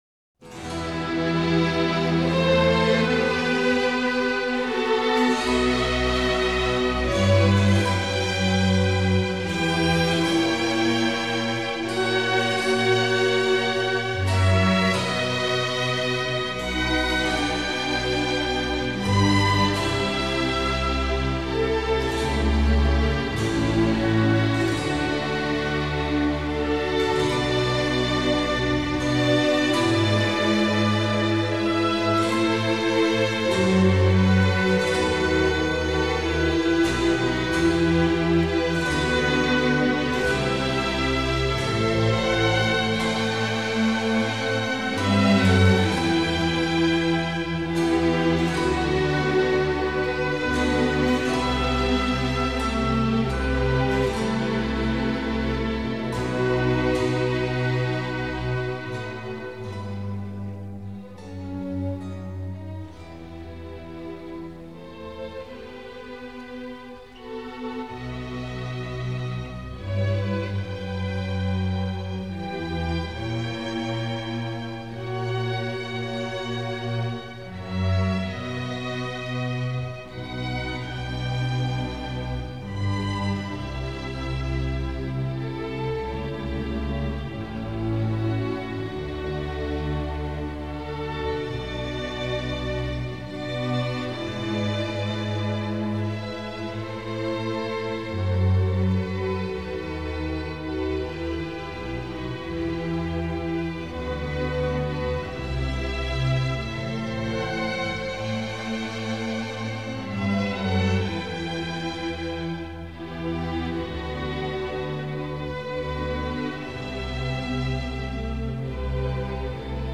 New recordings of Renaissance, Baroque and Classical repertoire have brought many of these ideas to life with performances that are exuberant, captivating, vibrant and joyful.
Academy of St. Martin in the Fields, Sir Neville Marriner, conductor, 1976
London Philharmonic Orchestra, Walter Susskind, conductor, 2002